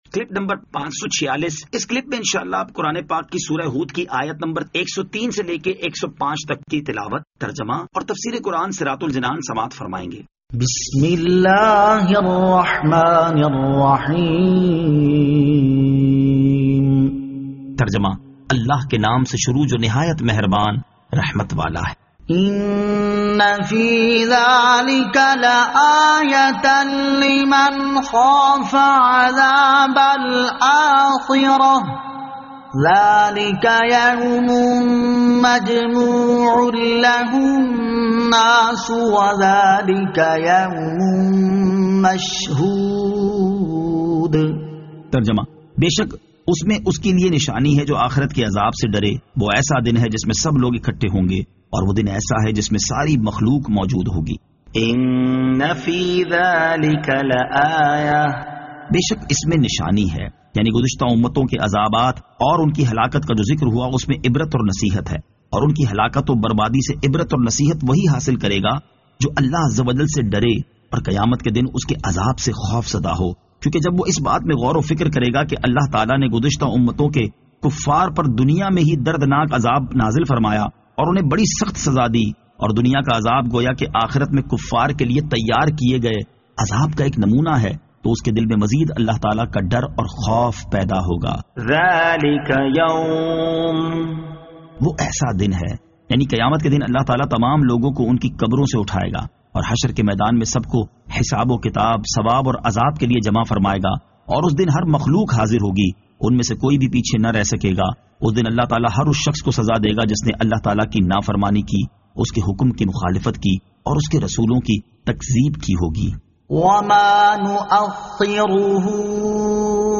Surah Hud Ayat 103 To 105 Tilawat , Tarjama , Tafseer